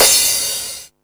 Index of /VEE/VEE Cymbals/VEE Crashes
VEE Crash 06.wav